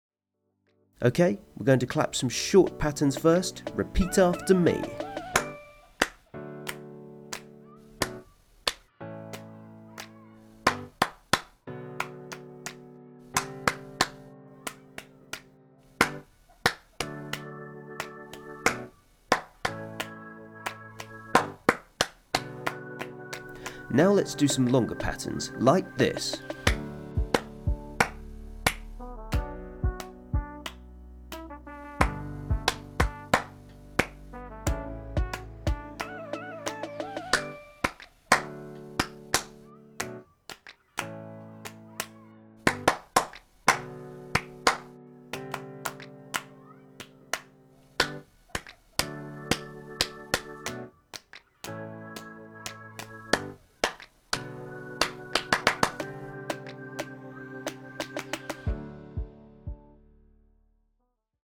You can use the In me I trust Rap activity – clapping example audio track to help with this.
Sing-Up-Day-2025-Rap-activity-clapping-example.mp3